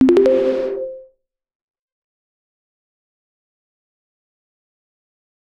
MenuOK2.wav